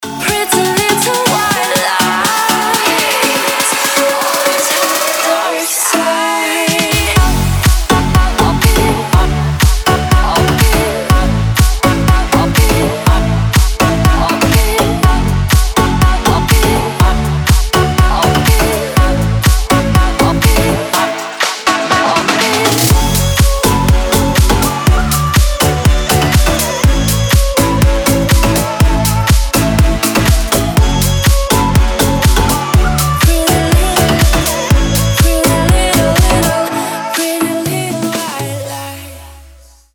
красивые
женский вокал
dance
Electronic
house